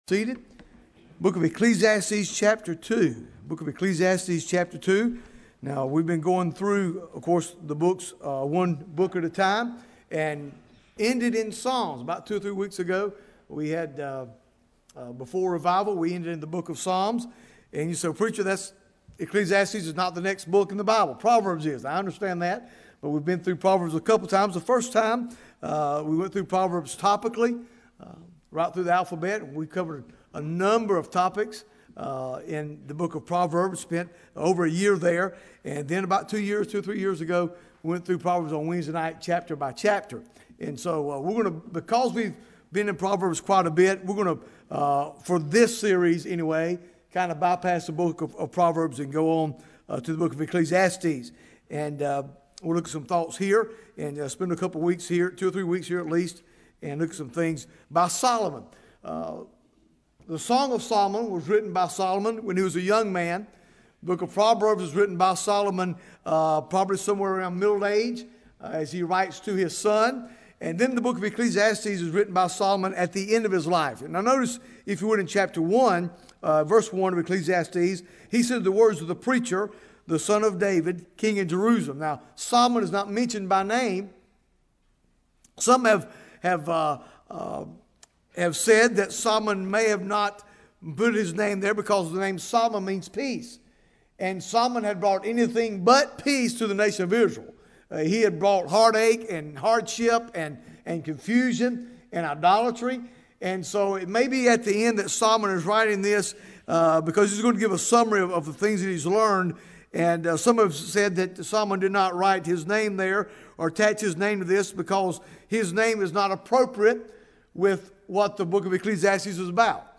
Bible Text: Ecclesiastes 3 | Preacher